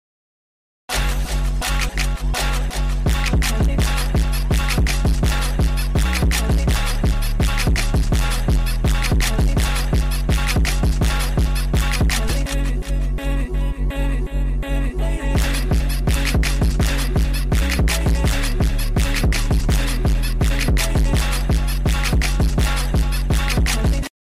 jersey club